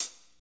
Index of /90_sSampleCDs/Northstar - Drumscapes Roland/DRM_Slow Shuffle/KIT_S_S Kit 2 x
HAT S S H0HR.wav